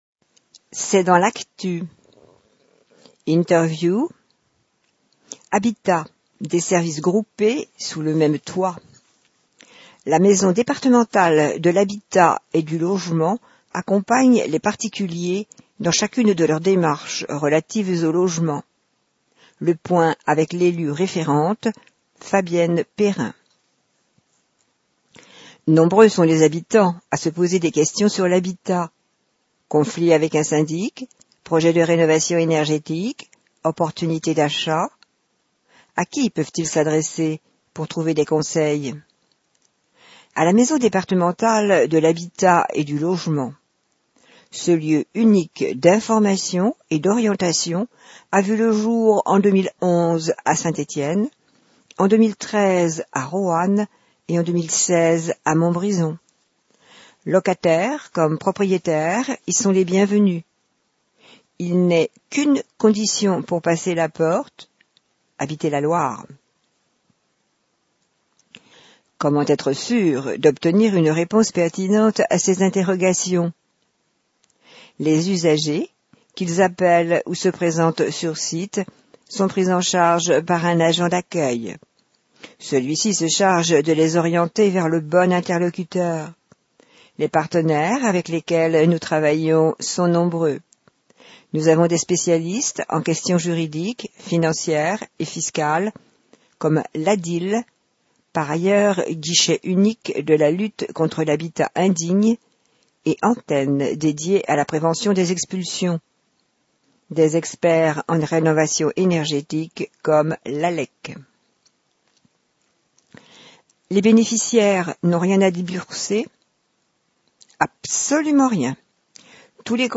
[C'EST DANS L'ACTU LM157] Interview sur l'habitat et Actu sur le rugby - mai-juin 2023